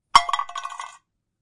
碰撞 罢工和捶打 " 碰撞09奶酪刨花机
描述：金属奶酪研磨器掉落。 用ECM99录入Extigy声卡。需要一些元素给一个人撞上一些垃圾。意外地将留声机罐装在调音台上 可能存在60周期的嗡嗡声和嘶嘶声。使用降噪来减少一些。
标签： 碰撞 SoundEffect中
声道立体声